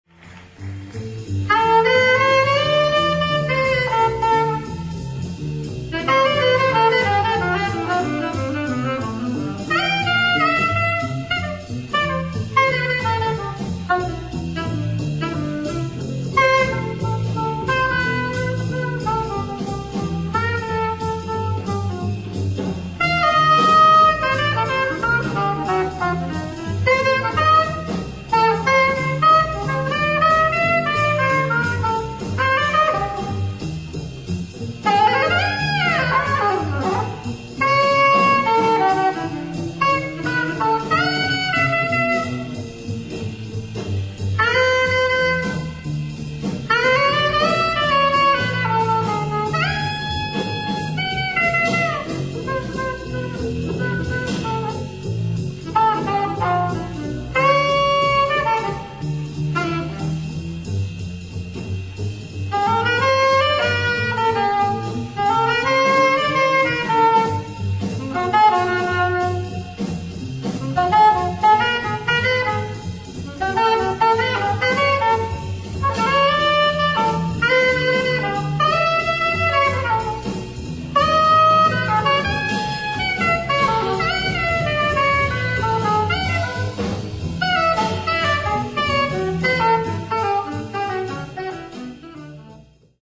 ディスク３＆４：・バークリー・パフォーマンス・センター、ボストン、マサチューセッツ 05/11/1986
※試聴用に実際より音質を落としています。